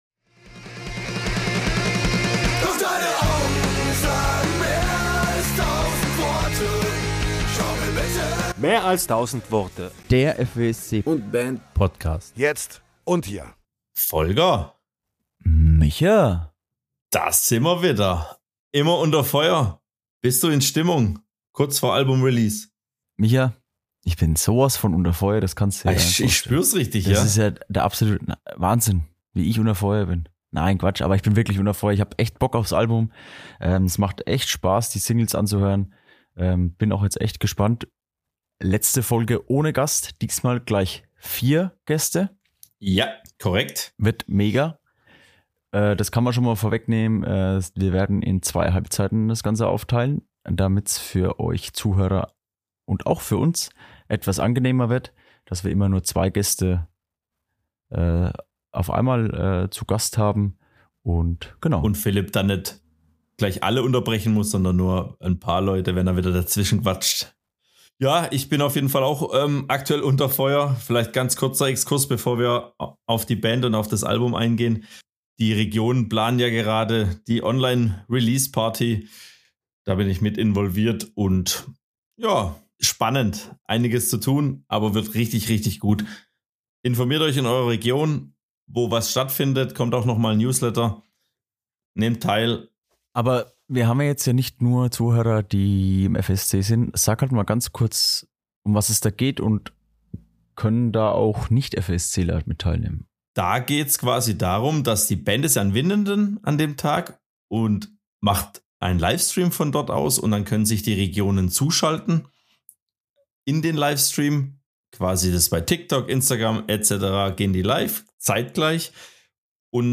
Ihr habt uns ordentlich mit Fragen eingedeckt und wir haben sie der Band gestellt, aufgeteilt in zwei spannende „Halbzeiten“:
Neben den verschiedensten Fragen entwickelte sich durch lebendige Gespräche ein spannender Austausch mit vielen exklusiven Einblicken.